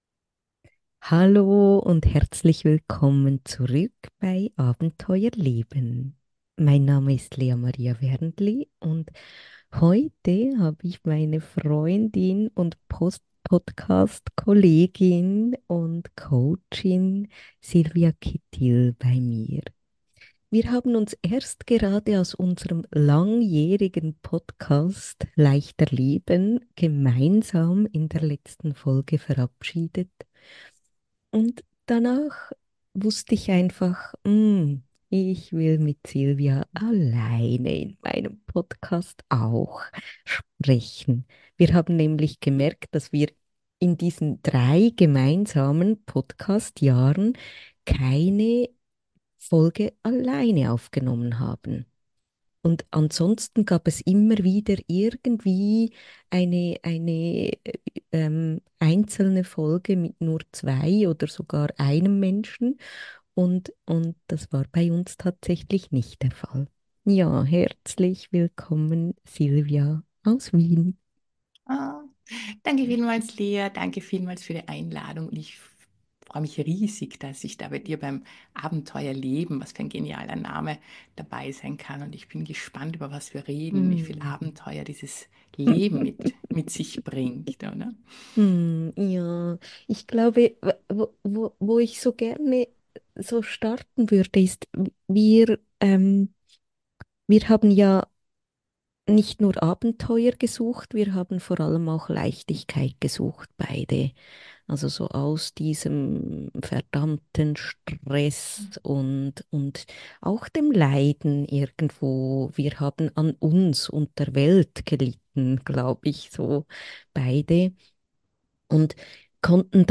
Ein inspirierendes Gespräch für alle, die mehr Leichtigkeit und inneren Frieden suchen.